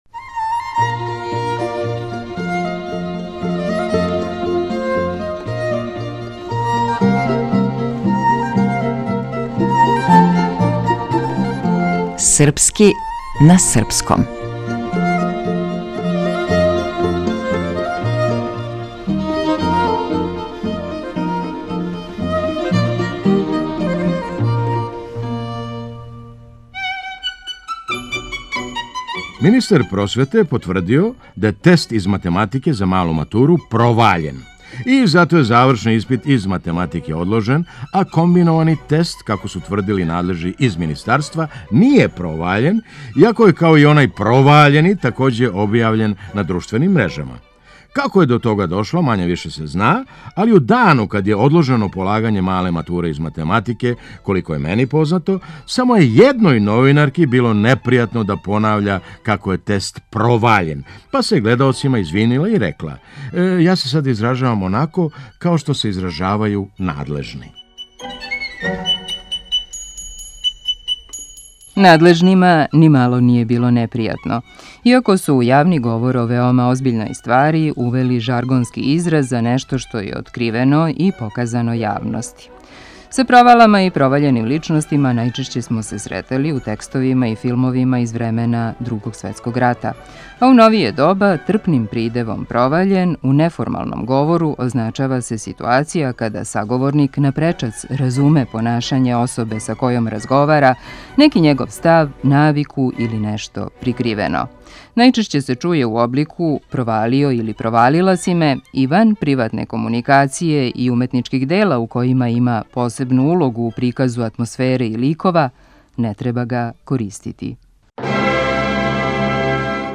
Глумац: Феђа Стојановић.